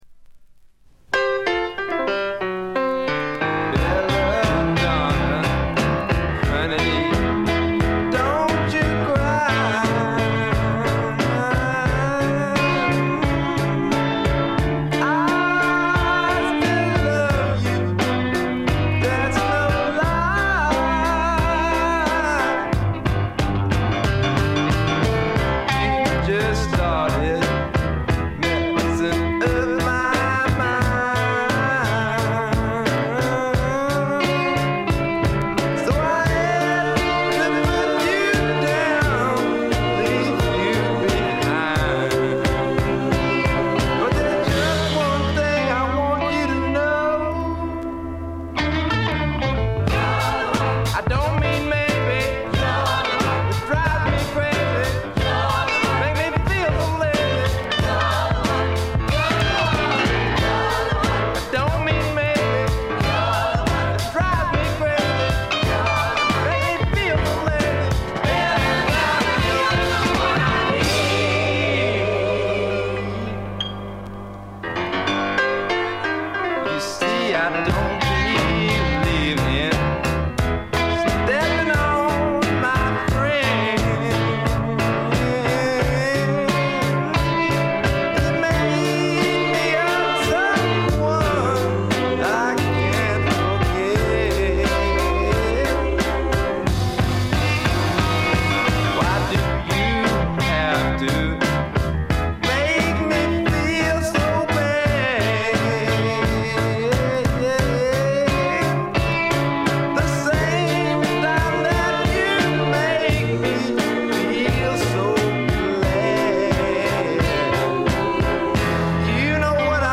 ごくわずかなノイズ感のみ。
まさしくスワンプロックの真骨頂。
試聴曲は現品からの取り込み音源です。
Vocal, Guitar, Keyboards